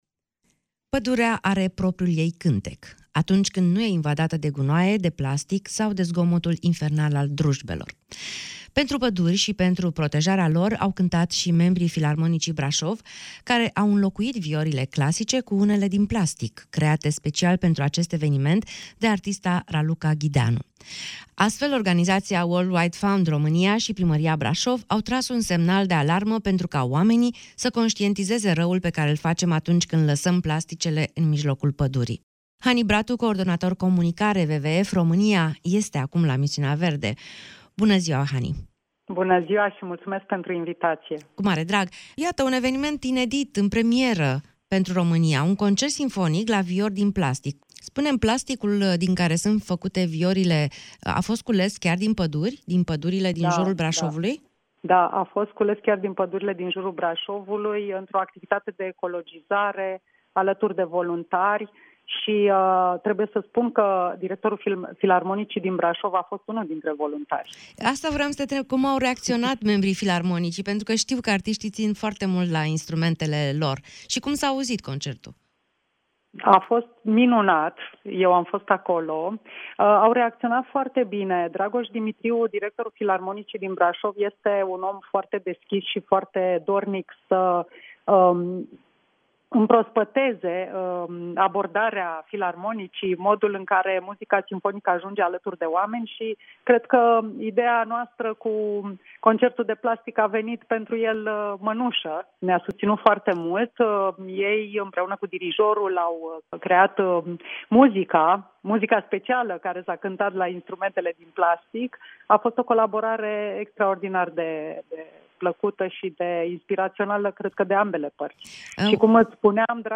Medalia Verde – Brașov: Concert simfonic susținut la viori făcute din plasticul adunat din păduri | AUDIO